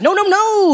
Tags: aziz ansari aziz ansari comedian